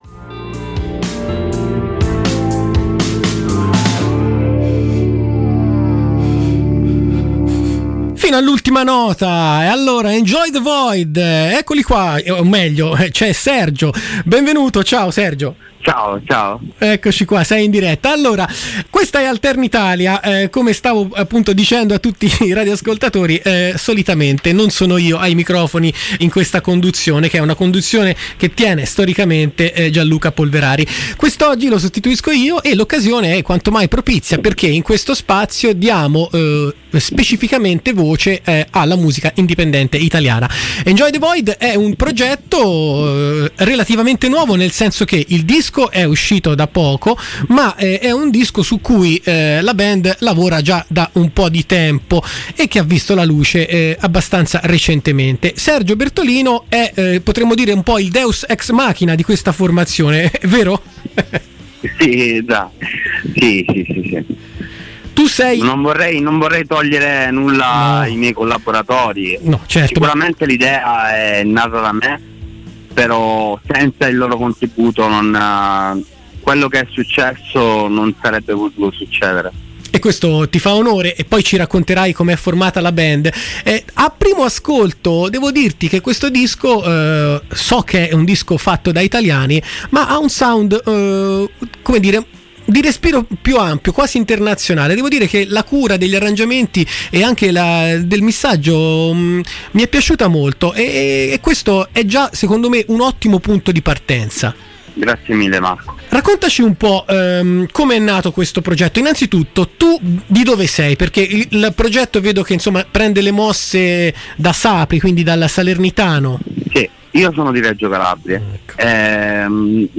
intervista-enjoythevoid.mp3